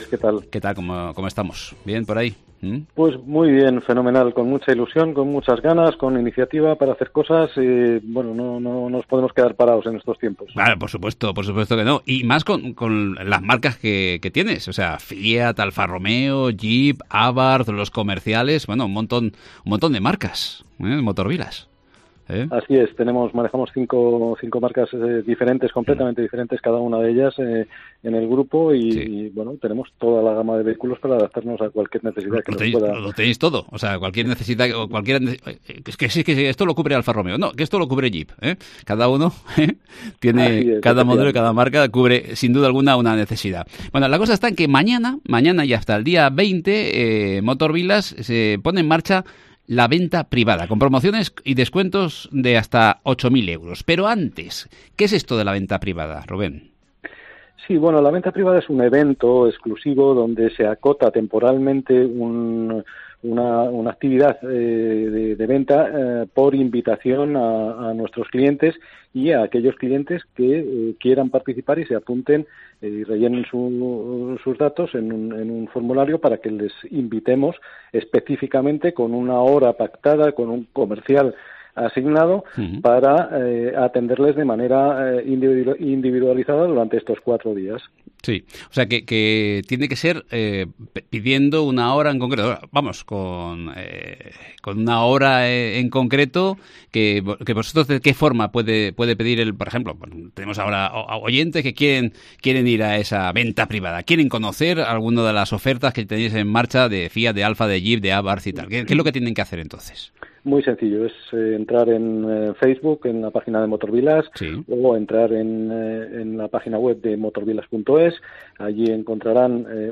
Entrevista Motor Village